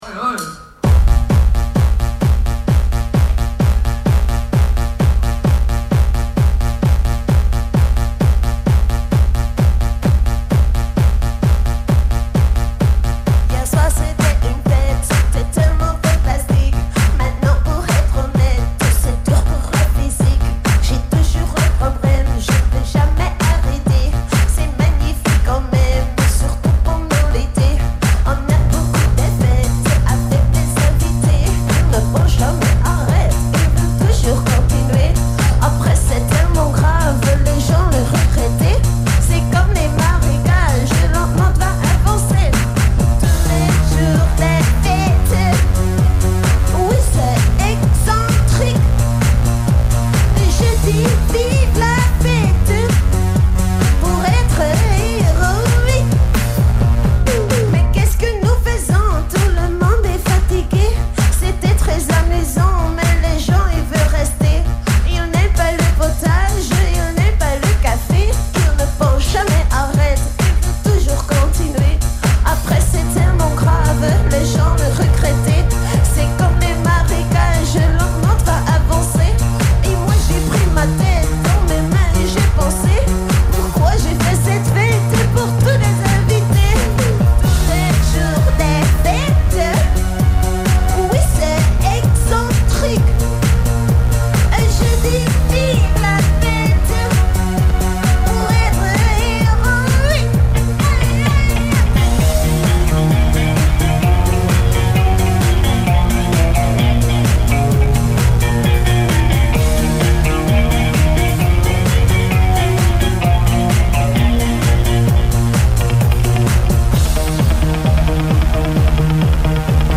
enregistrée le 28/06/2004  au Studio 105